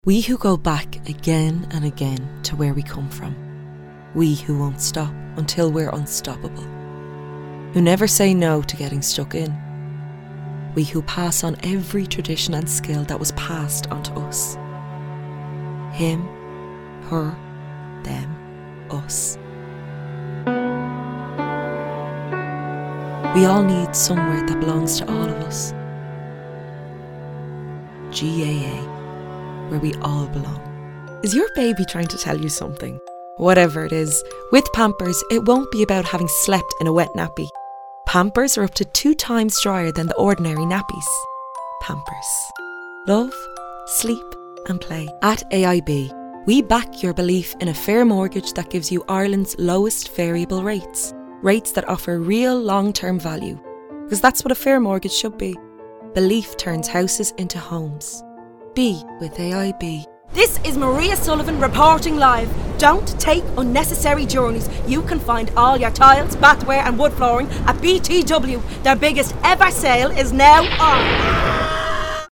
Female
20s/30s, 30s/40s
Irish Dublin Neutral, Irish Dublin City, Irish Neutral